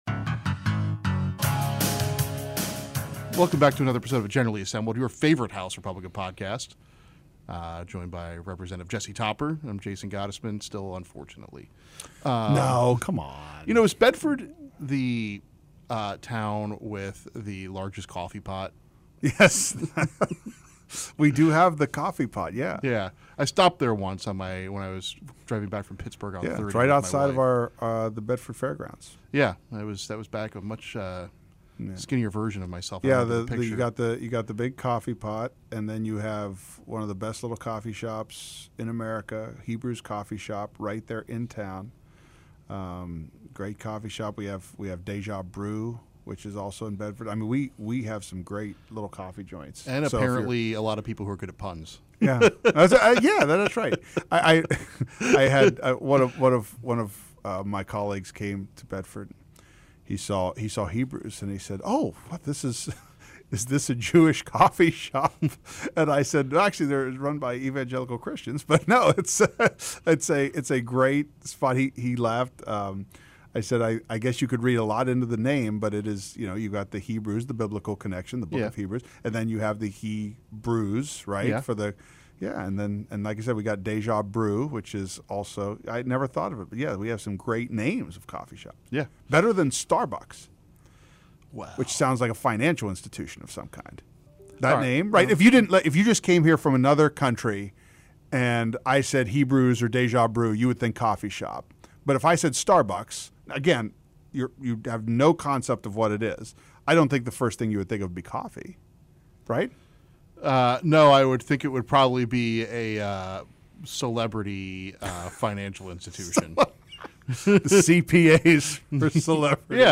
Interviews with lawmakers on legislation and current news